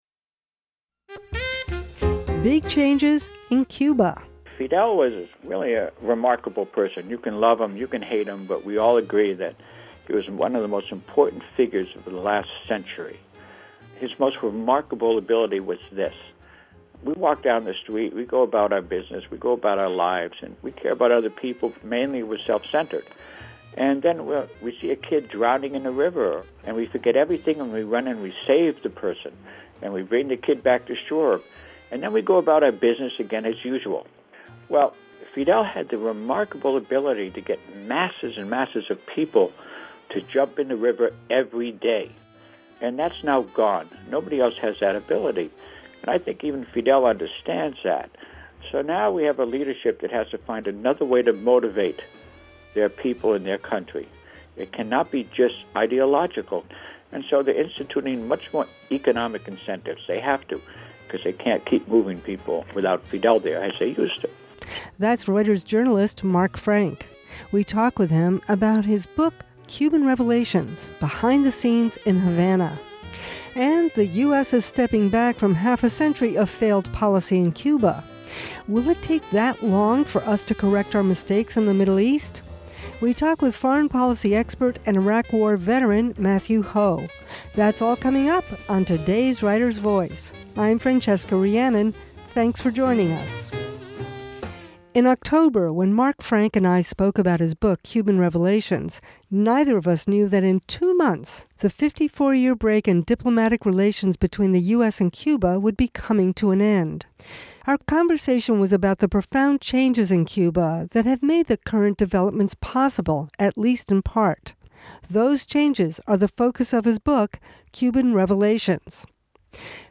book podcast, author interview Read more